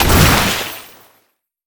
water_blast_projectile_spell_01.wav